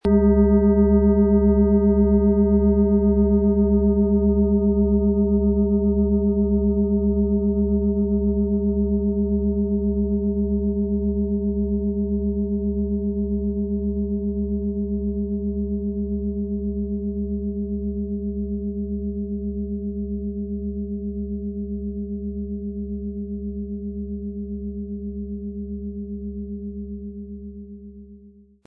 Nach überlieferter Tradition hergestellte Klangschale mit Biorhythmus Körper.
• Mittlerer Ton: Uranus
Um den Originalton der Schale anzuhören, gehen Sie bitte zu unserer Klangaufnahme unter dem Produktbild.
PlanetentöneBiorythmus Körper & Uranus & Tageston (Höchster Ton)
MaterialBronze